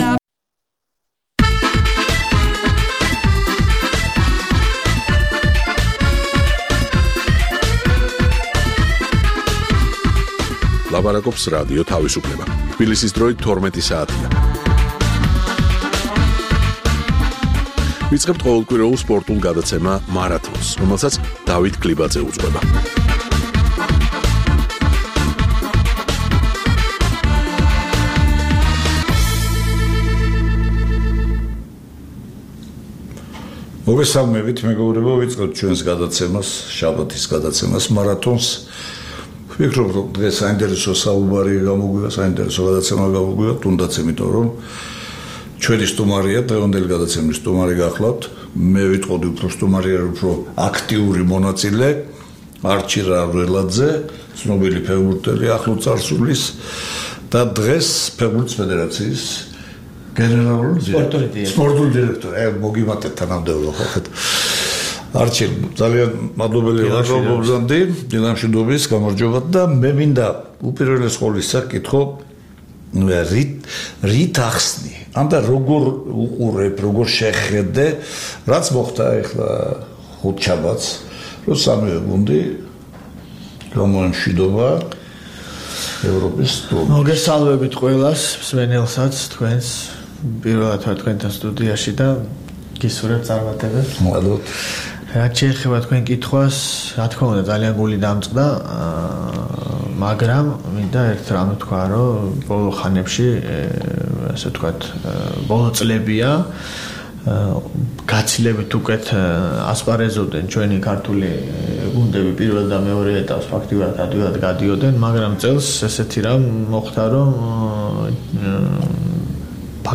მოისმენთ სატელეფონო საუბარს
სპორტული პროგრამა "მარათონი"